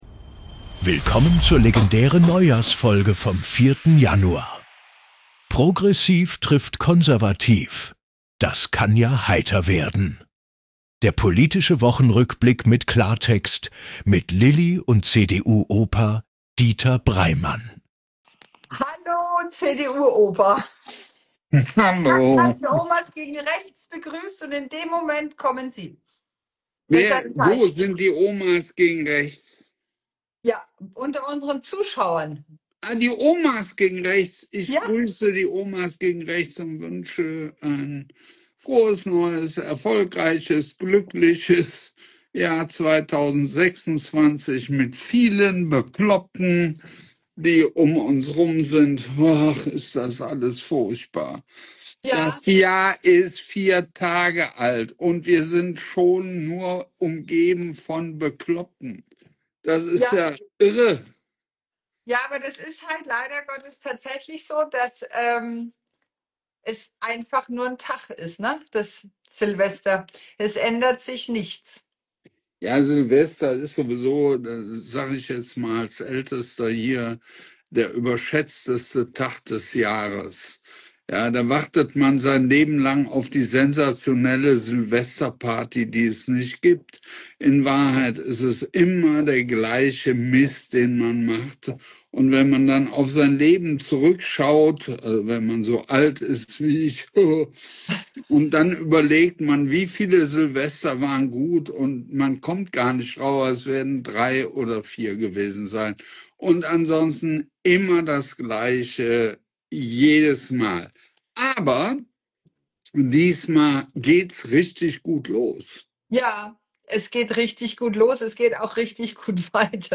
zwei Perspektiven, ein Gespräch